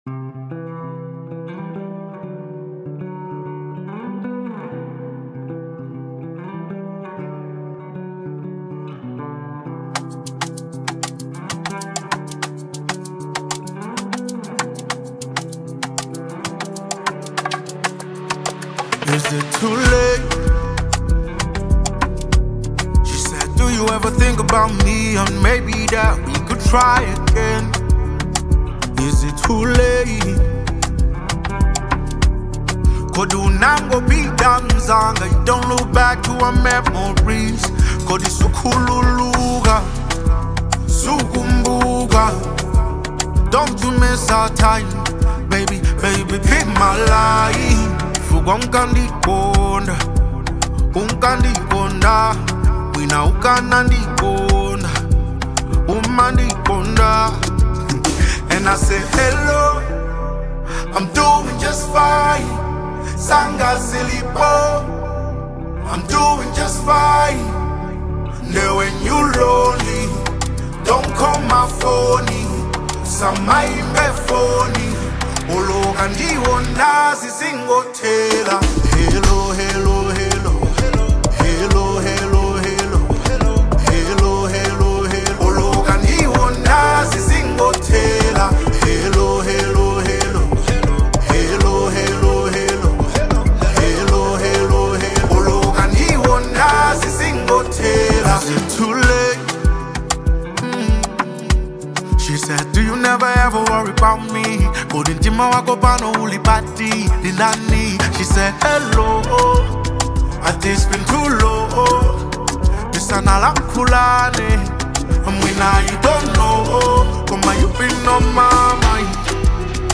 Genre : Afro Soul
vibrant and energetic opener